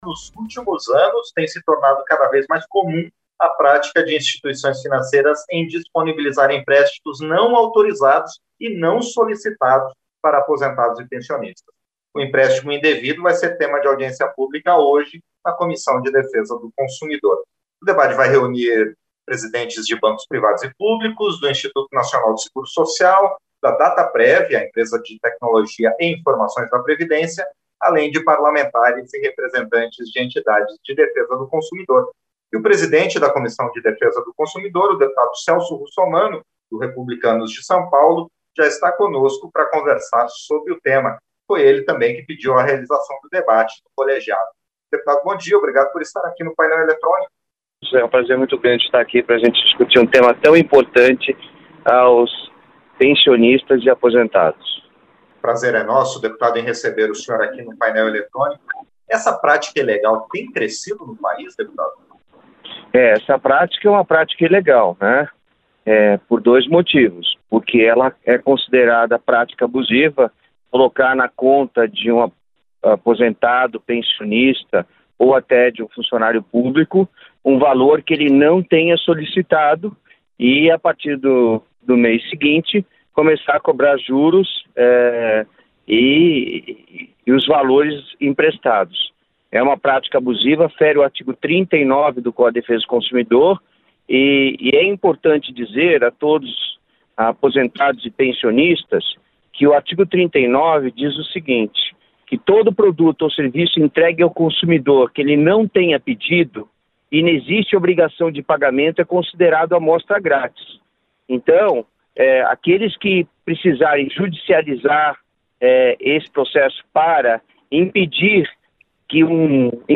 Entrevista - Dep. Celso Russomanno (Republicanos-SP)